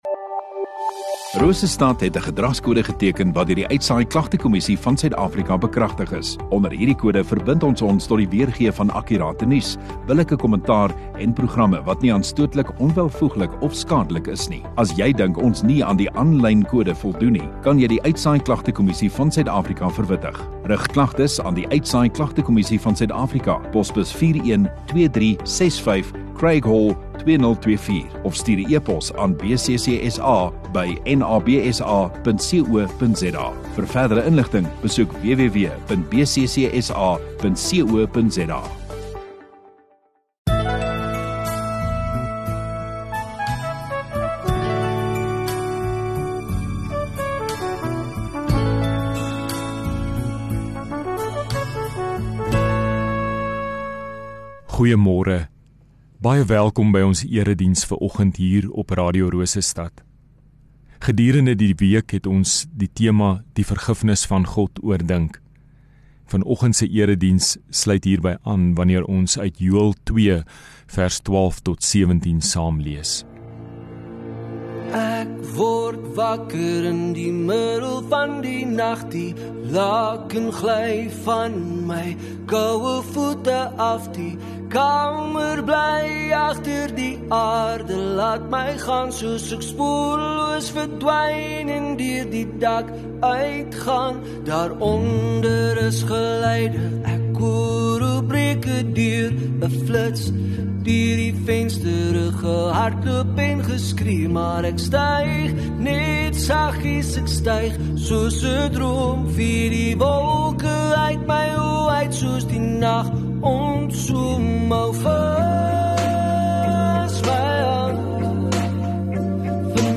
8 Sep Sondagoggend Erediens